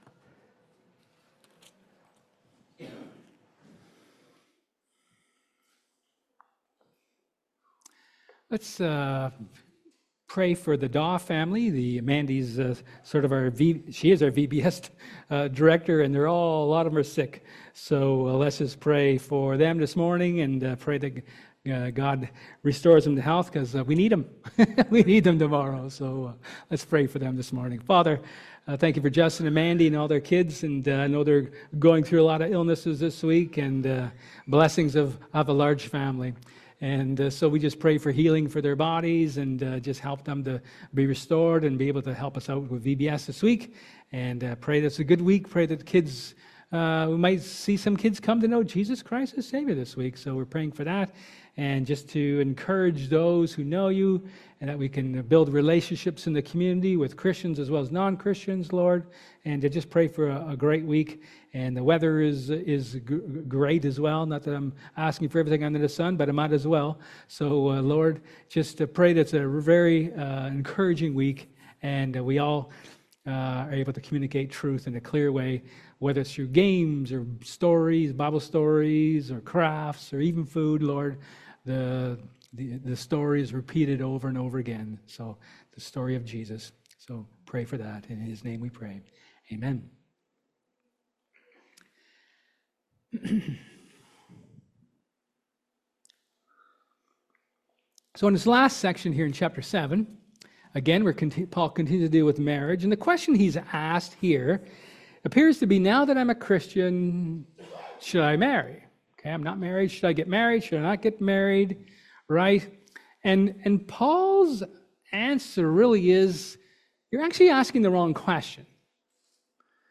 1 Corinthians 14:26-40 Service Type: Sermon